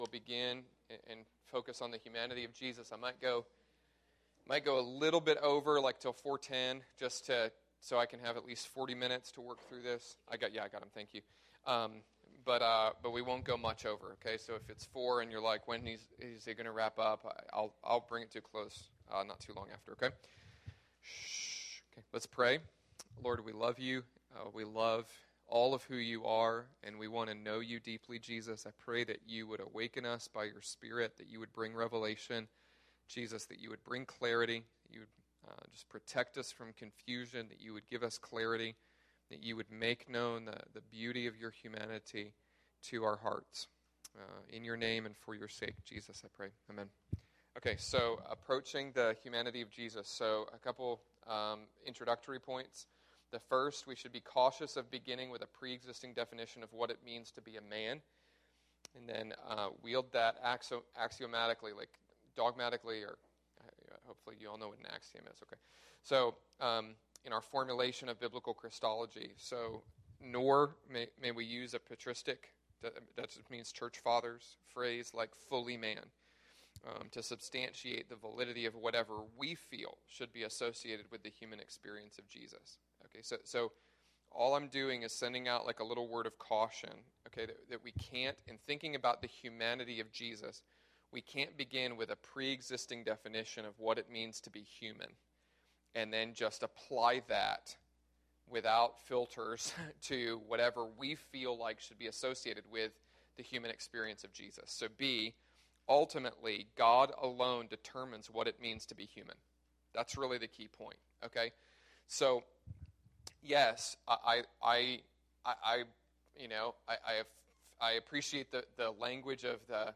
Spoken on September 15th, 2012 at the International House of Prayer East Bay .